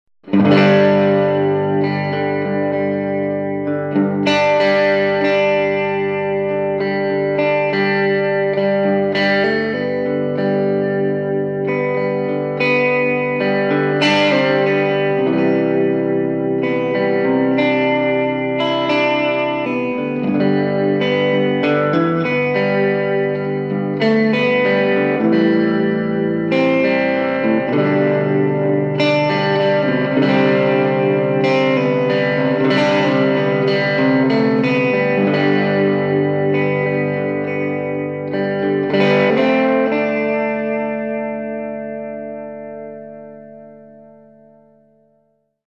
Room Nocastor Tele Clean 2   :46